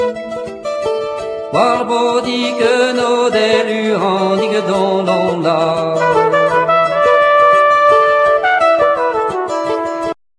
tourné vers le jazz